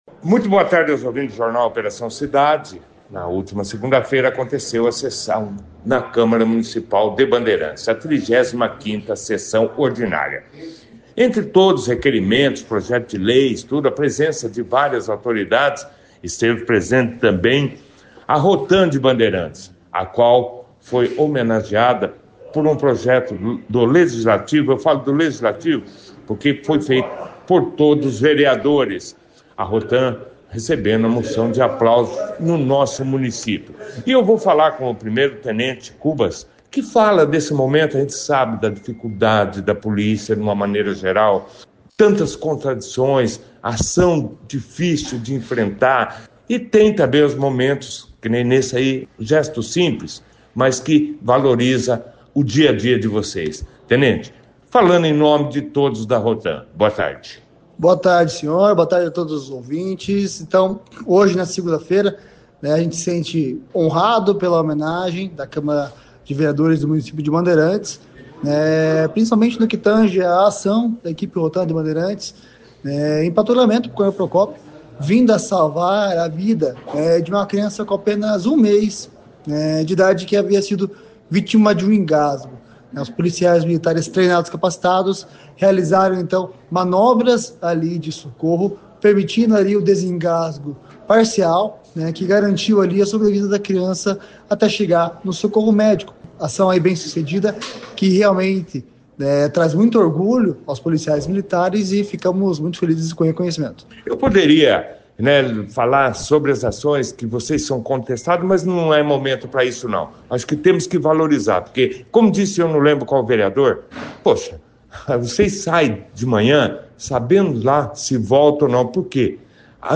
Realizada na última segunda-feira, 10 de novembro, a 35ª Sessão Ordinária da Câmara Municipal de Bandeirantes foi destaque na 2ª edição do Jornal Operação Cidade, desta terça-feira (11). A sessão contou com a participação dos vereadores, da equipe da ROTAM e de representantes da APP-Sindicato, reunindo autoridades e comunidade.